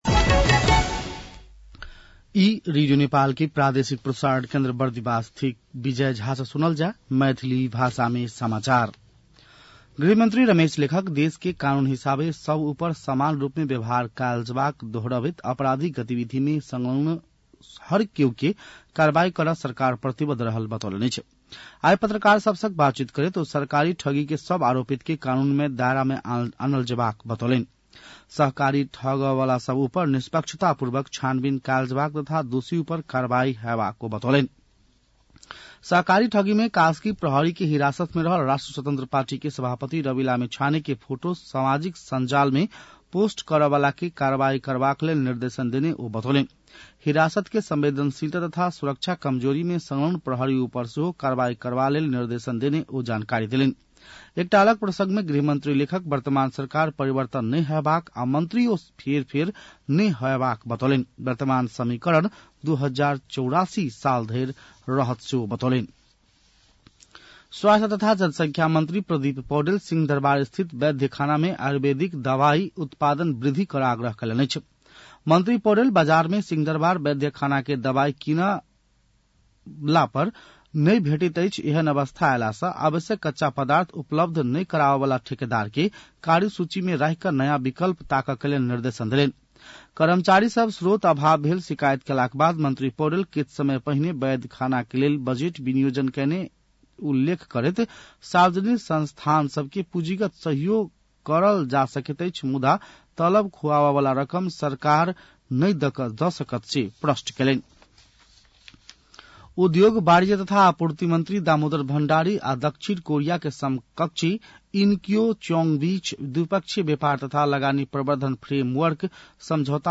मैथिली भाषामा समाचार : ५ पुष , २०८१
Maithali-news-9-04.mp3